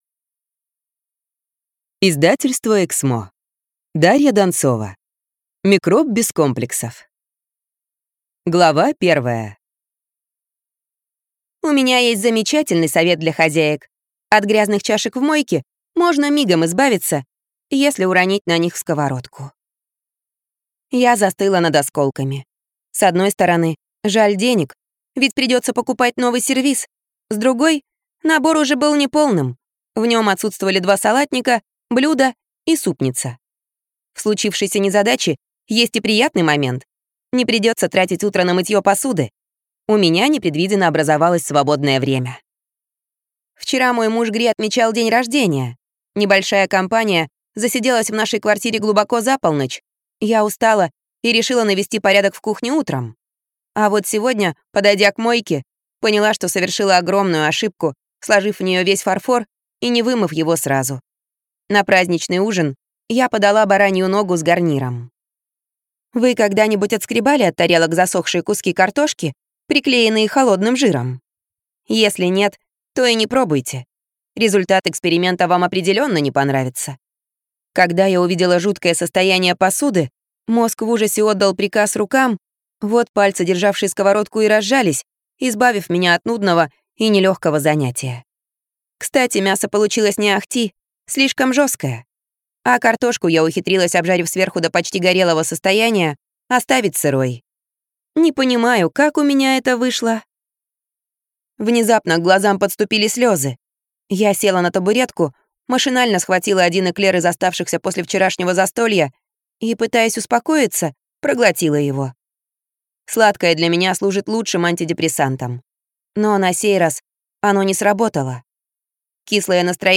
Аудиокнига Микроб без комплексов | Библиотека аудиокниг
Прослушать и бесплатно скачать фрагмент аудиокниги